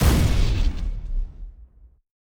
Sci-Fi Effects
weapon_blaster_004.wav